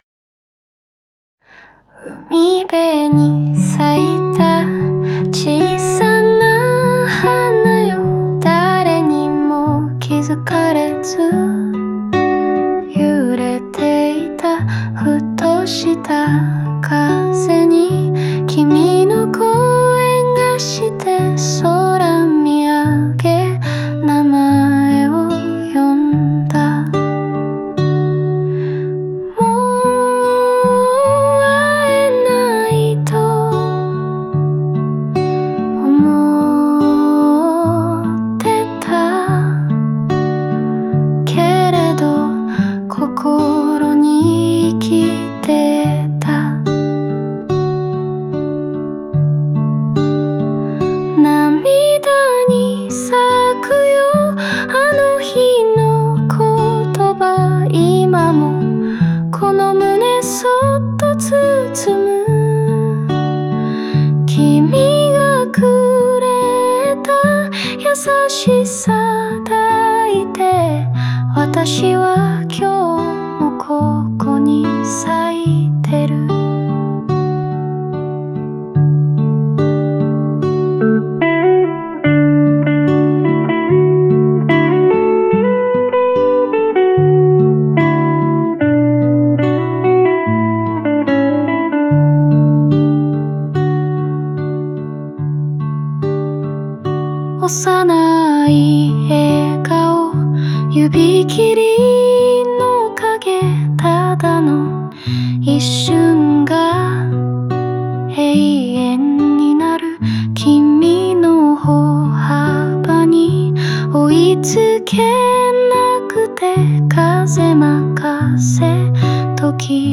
静かな回想から始まり、徐々に感情が高まりながらも、やさしさと温もりが全体を包んでいます。